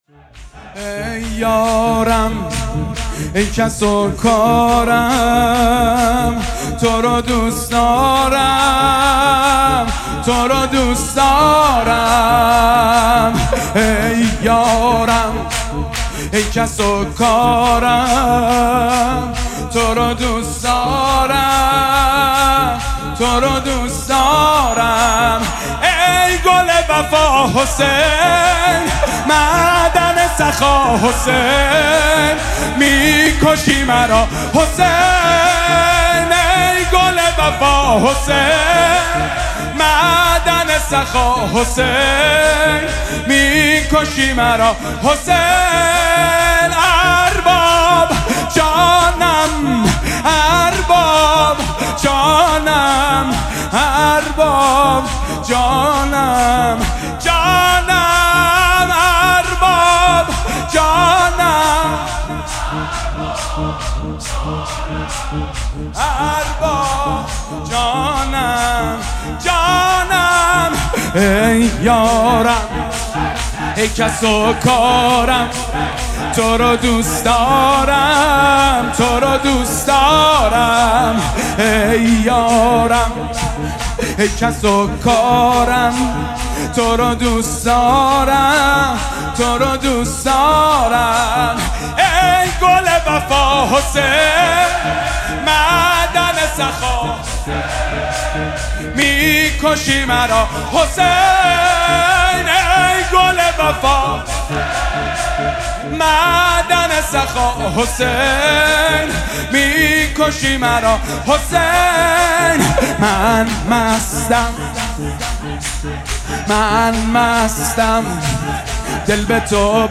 مداح